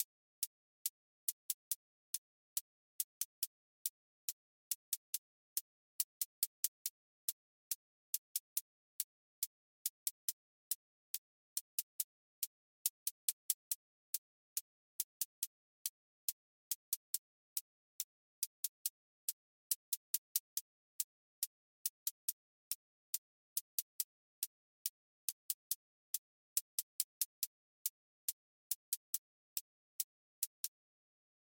Trap 808 tension with clipped hats